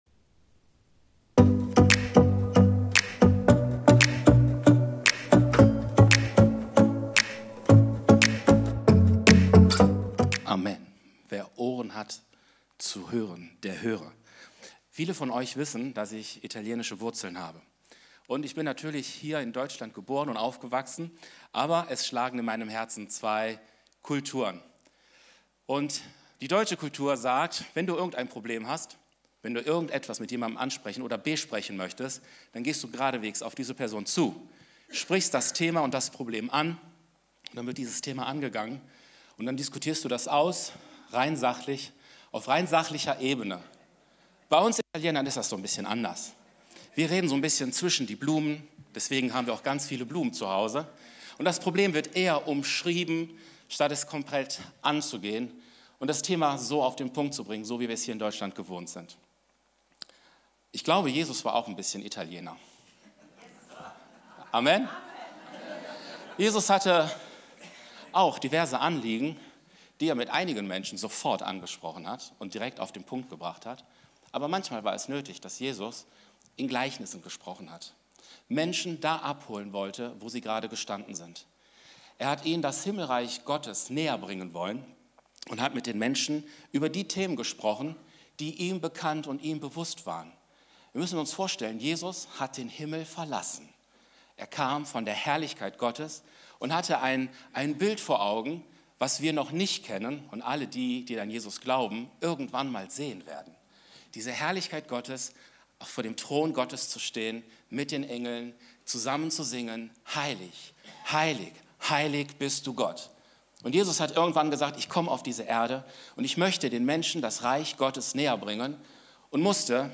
Video und MP3 Predigten
Kategorie: Sonntaggottesdienst Predigtserie: Die Gleichnisse des Königs